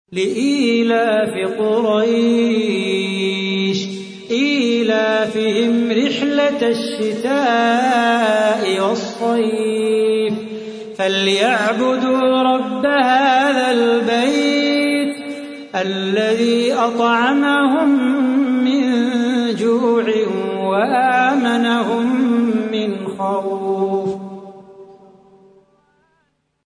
تحميل : 106. سورة قريش / القارئ صلاح بو خاطر / القرآن الكريم / موقع يا حسين